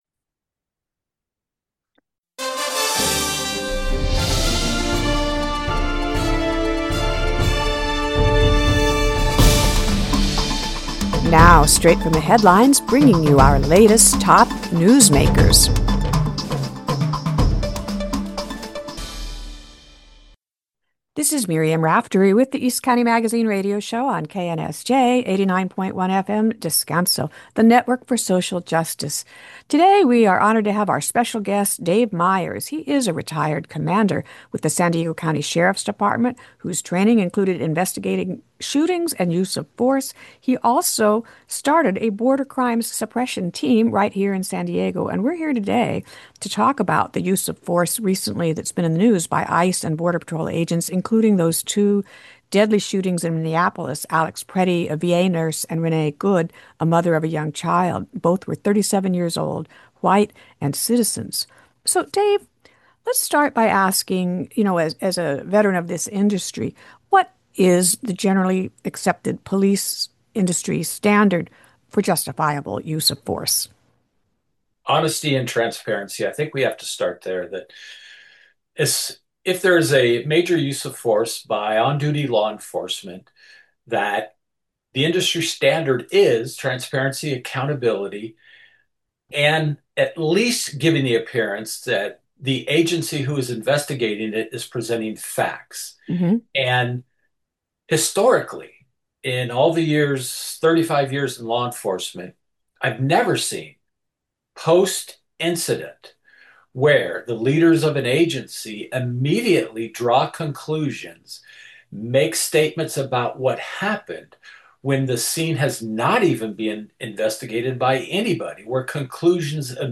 Hear our interview, originally aired on KNSJ radio